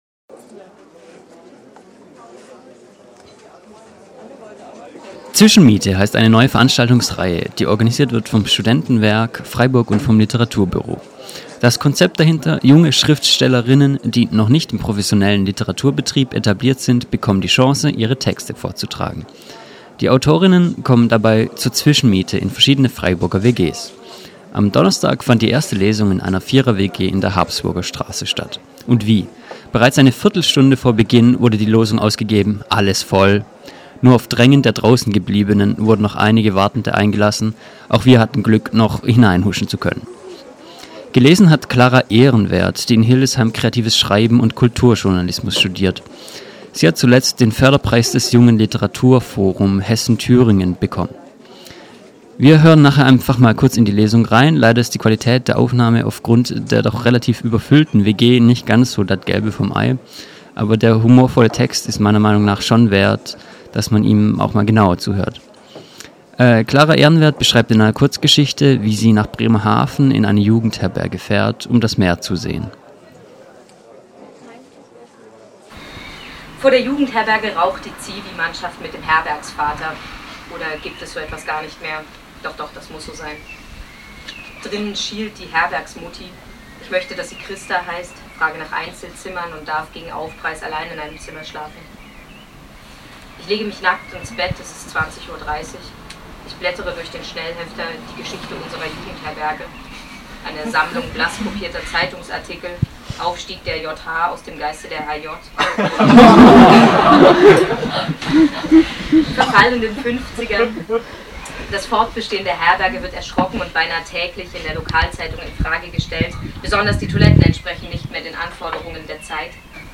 Zwischenmiete - Lesung